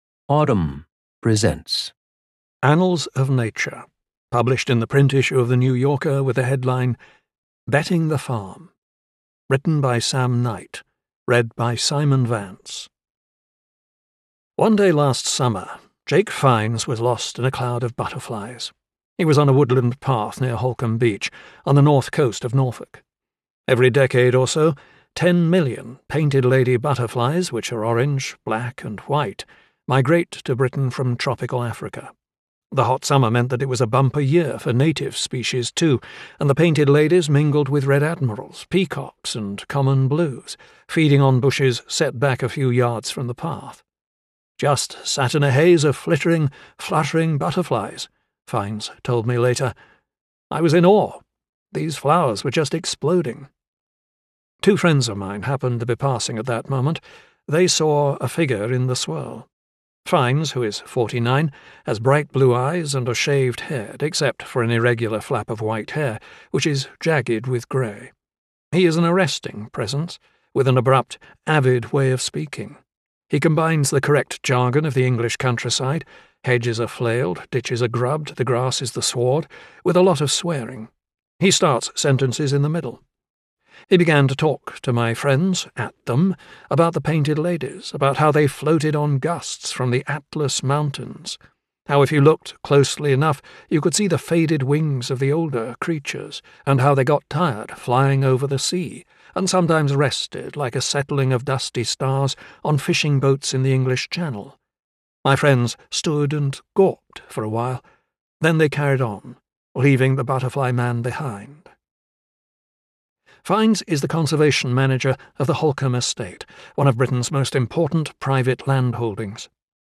I’m a big fan of this trend to have these stories read aloud.